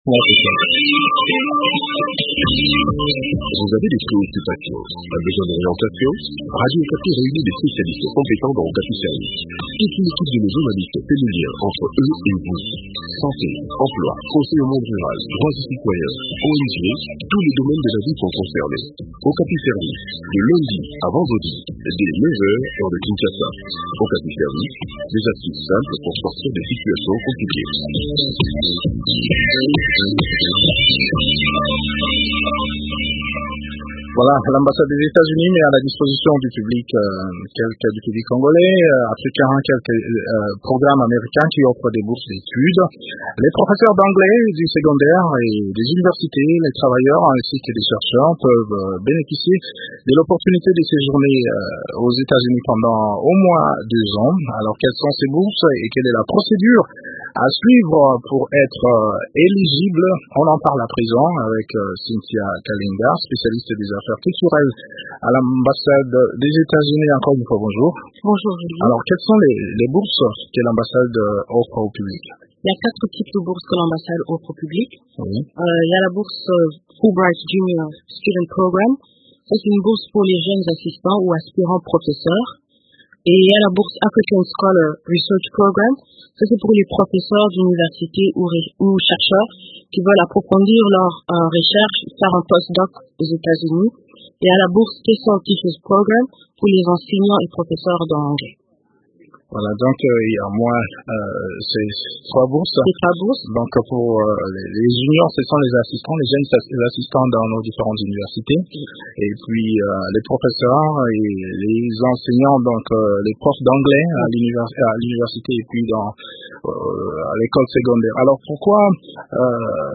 Le point du sujet dans cet entretien